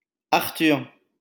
French: [aʁtyʁ]